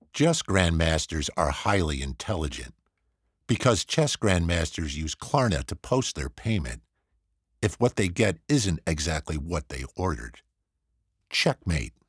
Bank Commercial tongue and cheek
neutral accent. middle age to senior.
*A Source Connect equipped professional sound booth.
*Broadcast-quality audio.